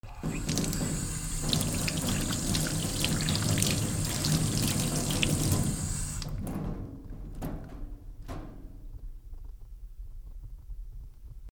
手を洗う 学校水道
MKH416